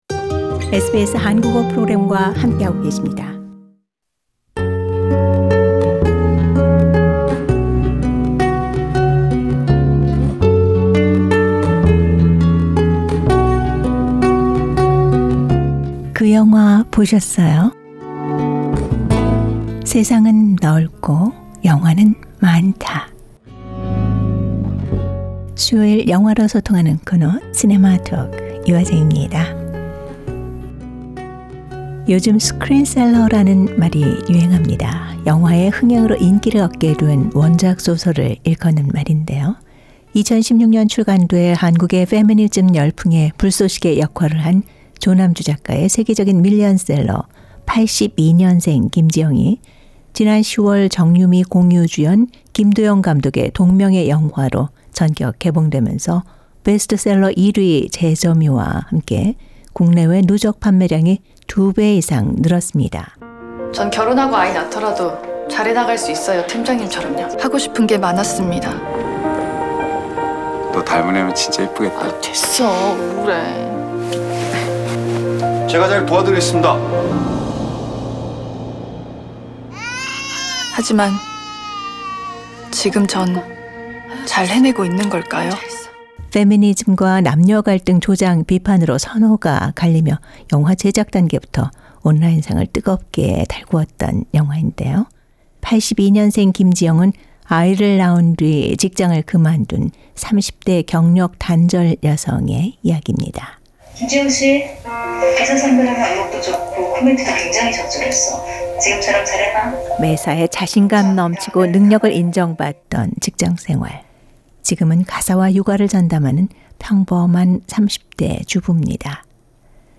영화가 끝난 후 극장 앞에서 관객 인터뷰를 시도했습니다.